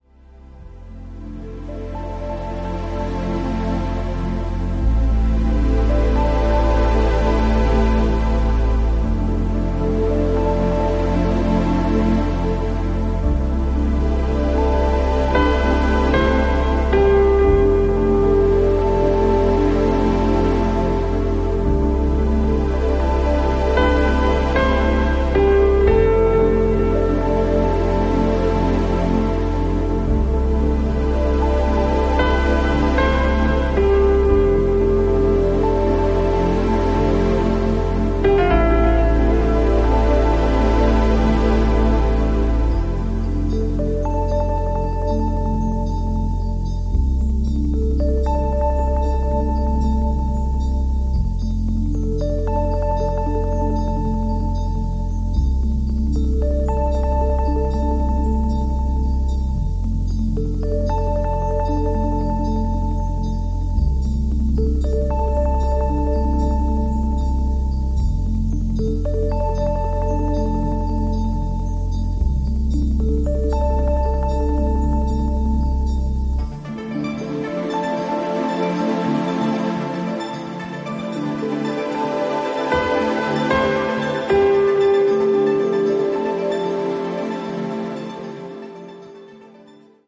今回、さらにリマスタリングを施したことで音質がさらに良くなっているそうですので(※ご本人談)、そのあたりもお楽しみに！